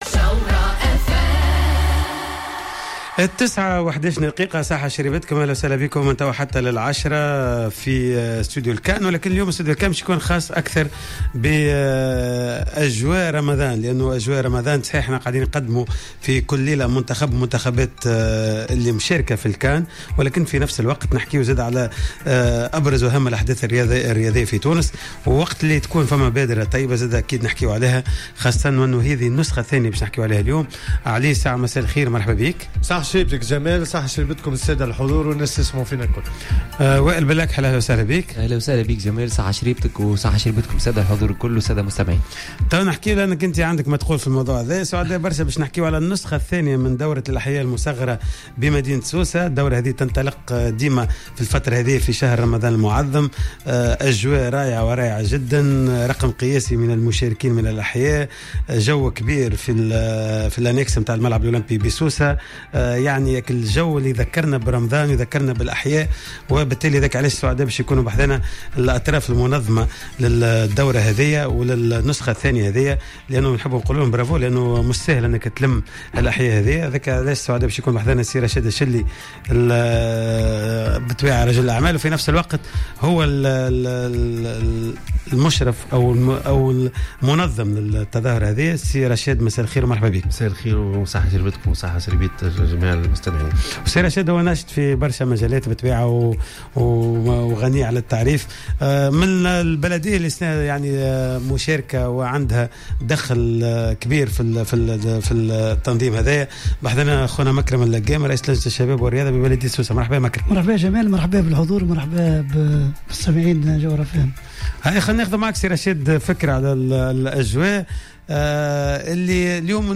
إستضافت حصة استوديو الكان منظمي النسخة الثانية من دورة الأحياء لكرة القدم...